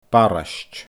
barraist /baRɪʃdʲ/